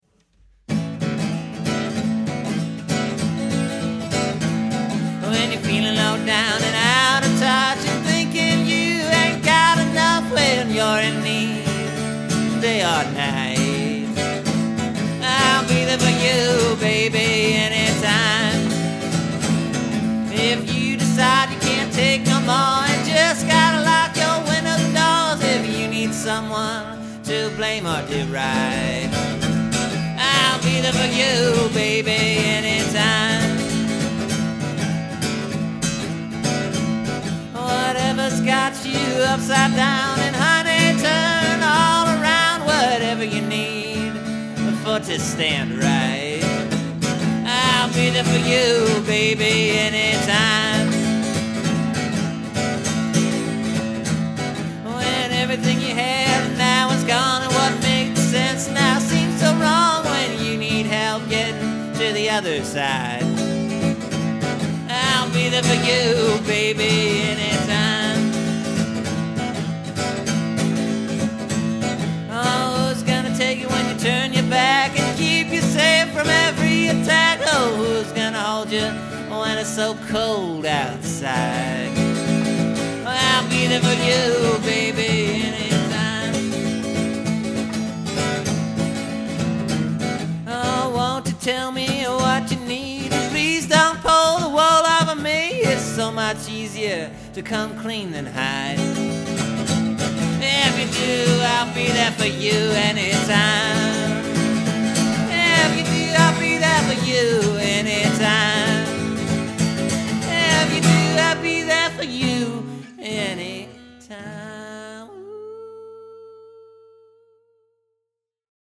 So, I rewrote a couple parts of this tune. The repeating chorus line is different and it kinda changes the way this song comes across.